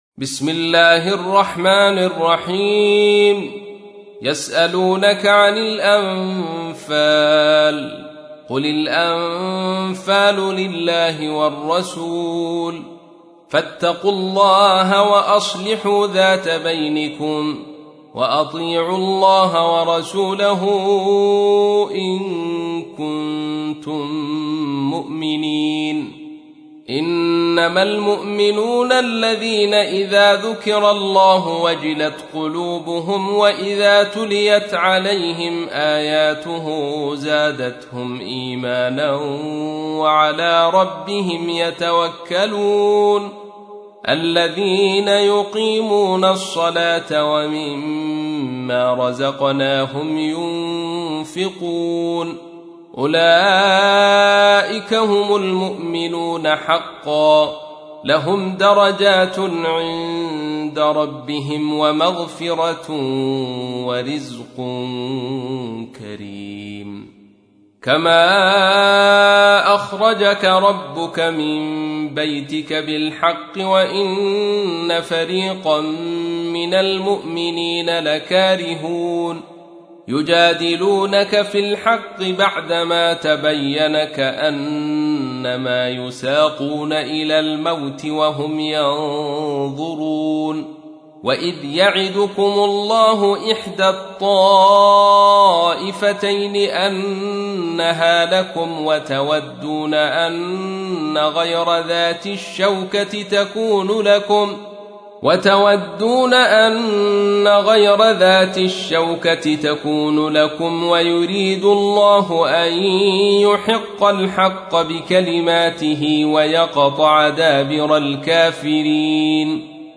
تحميل : 8. سورة الأنفال / القارئ عبد الرشيد صوفي / القرآن الكريم / موقع يا حسين